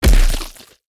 face_hit_finisher_19.wav